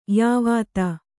♪ yāvāta